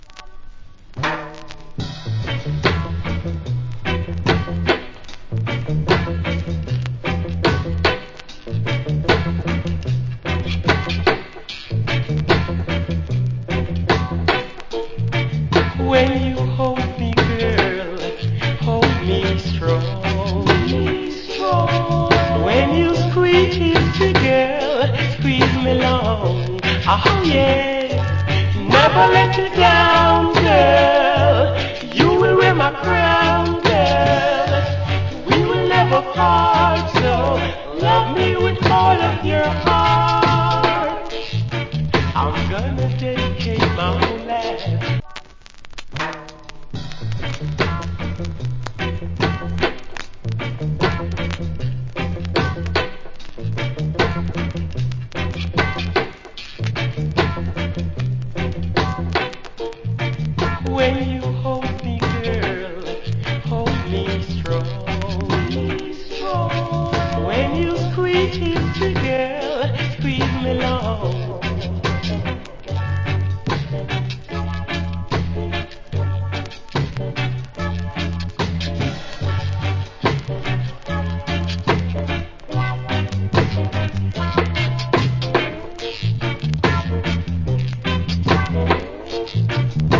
Old Hits Rock Steady Vocal.